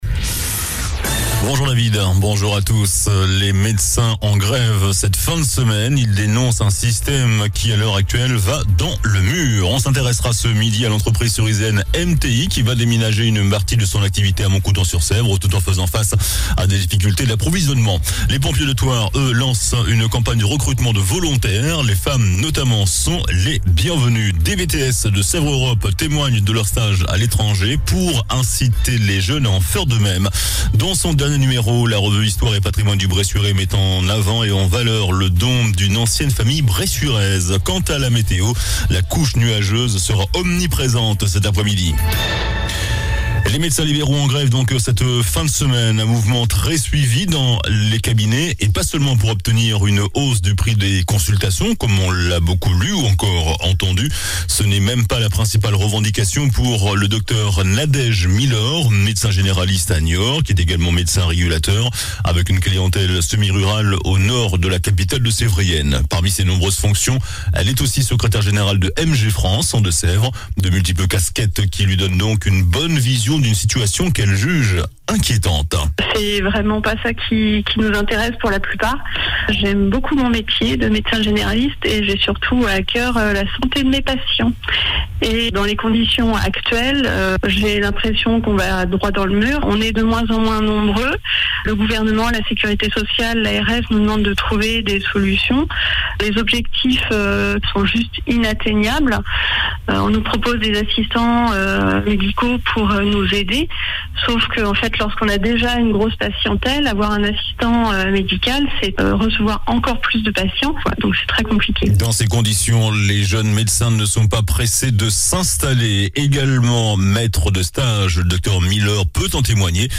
JOURNAL DU VENDREDI 02 DECEMBRE ( MIDI )